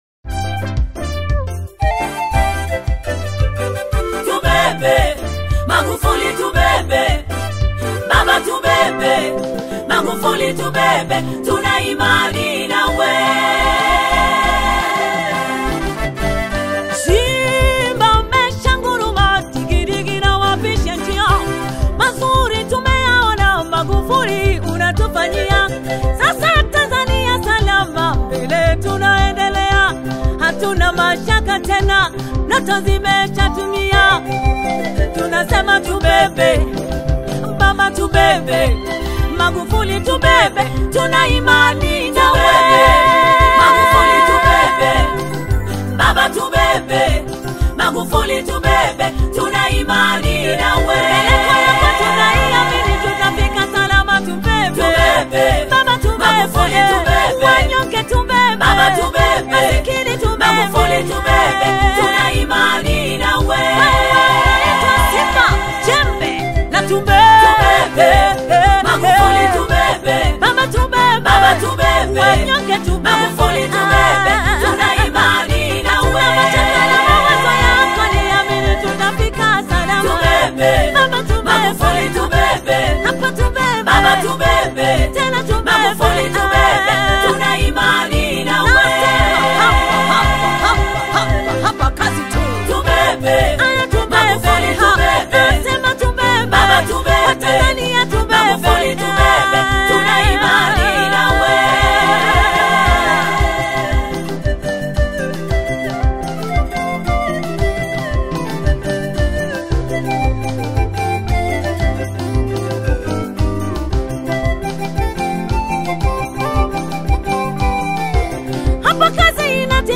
NEW GOSPEL AUDIO